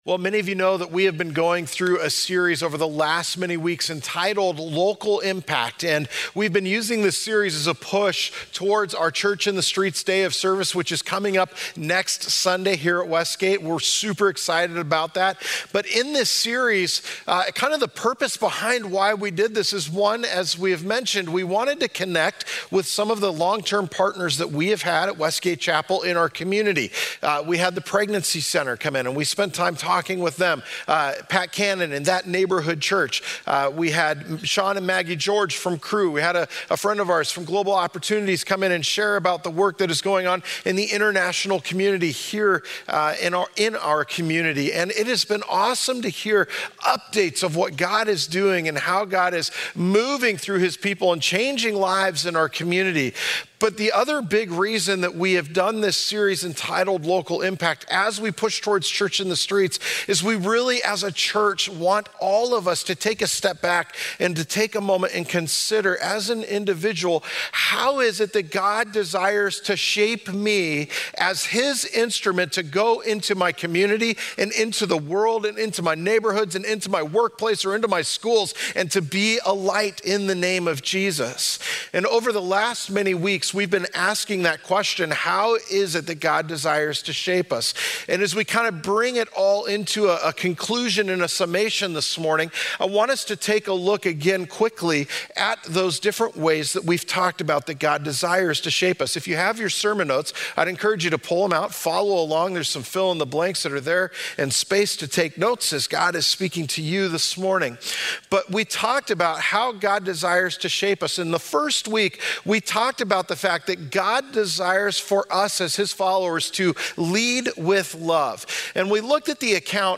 Westgate Chapel Sermons Local Impact - From Selfie to Servant Jun 16 2019 | 00:37:39 1x 00:00 / 00:37:39 Subscribe Share Apple Podcasts Overcast RSS Feed Share Link Embed